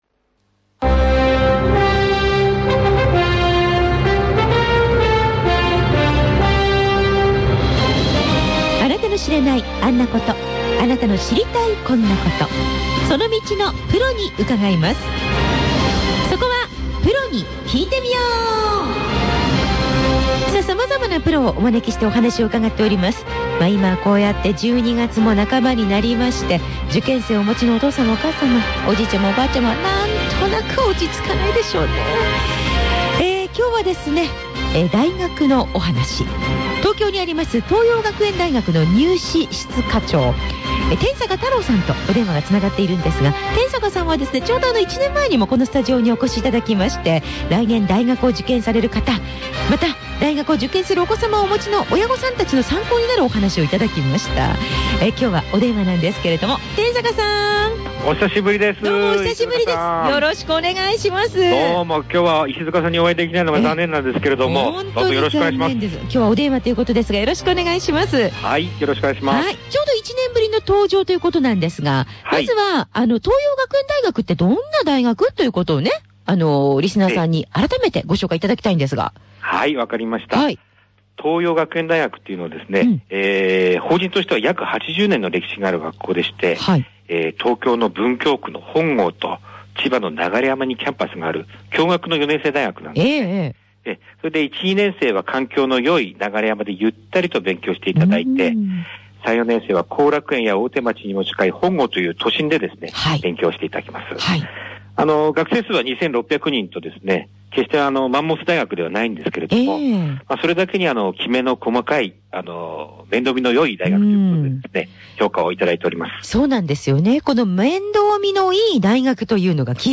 新潟県のラジオ局・ＢＳＮ新潟放送（AM1116KHz）で、地方入試ＰＲ。